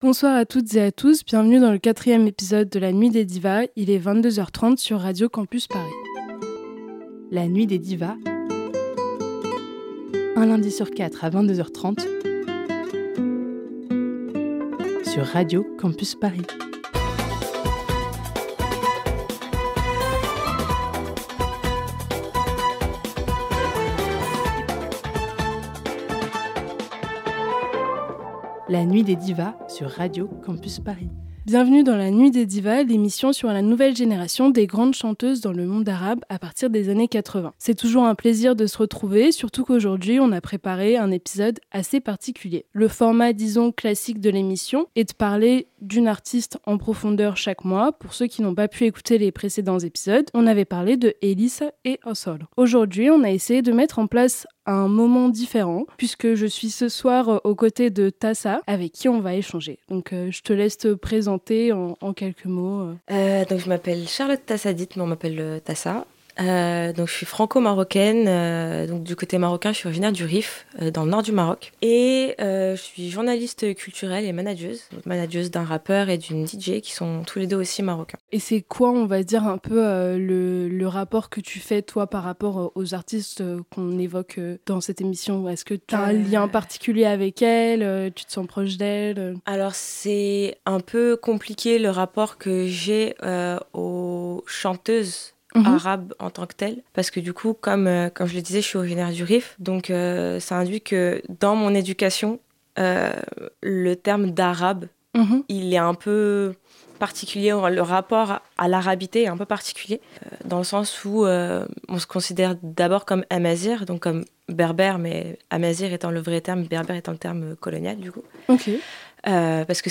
Au lieu de parler d’une artiste en profondeur, on a mis en place une discussion axée sur le débat